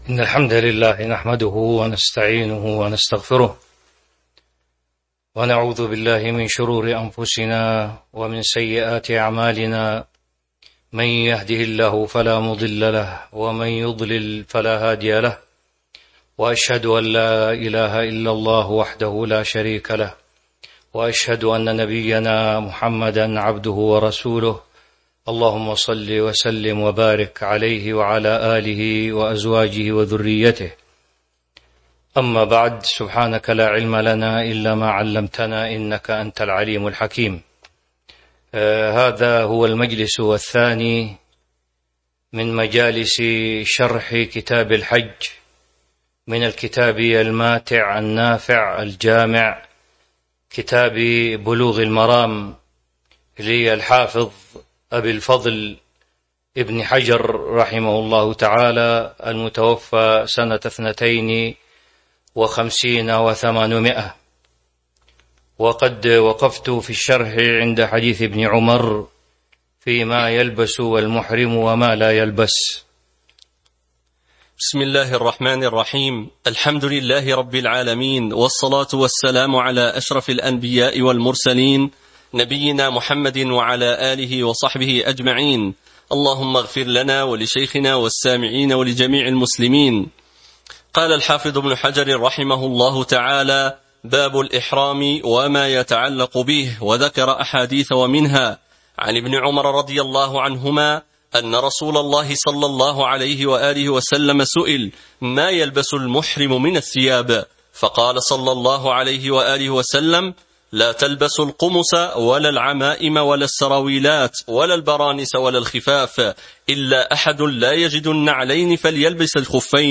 تاريخ النشر ٣٠ ذو القعدة ١٤٤٢ هـ المكان: المسجد النبوي الشيخ